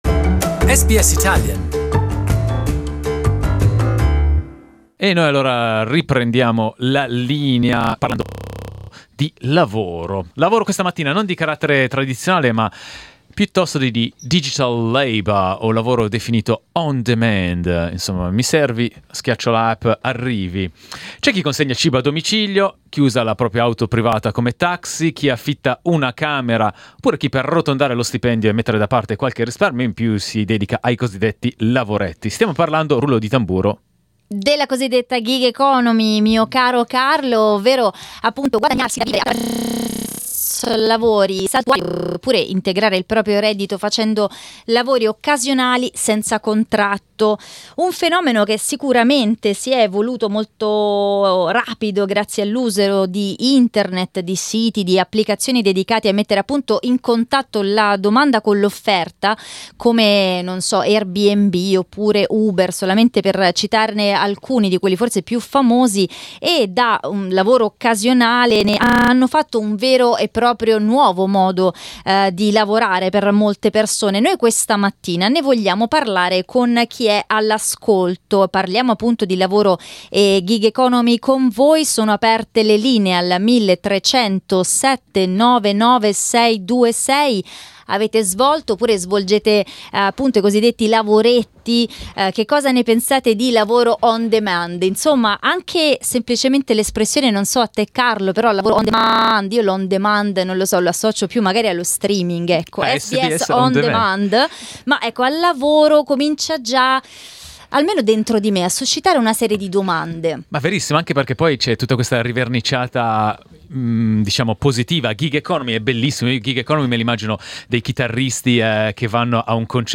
We talked about it this morning with our listeners and live guests.